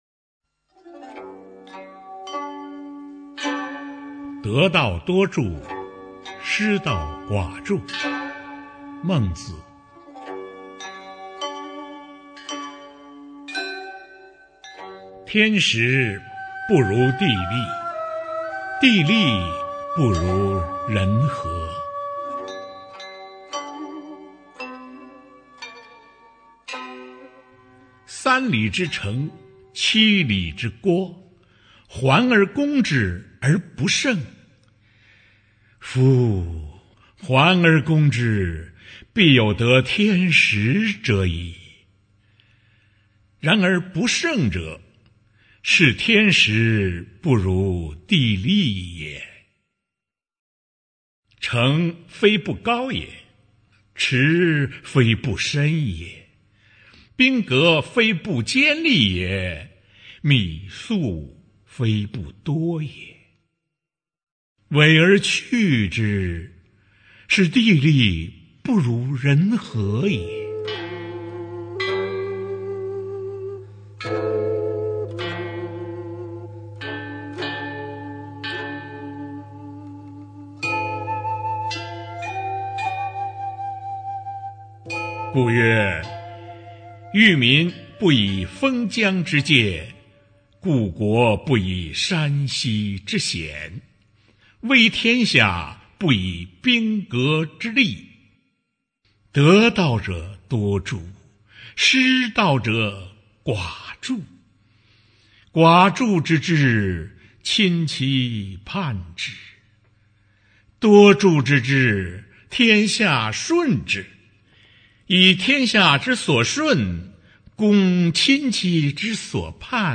《得道多助，失道寡助》原文及译文（含朗读）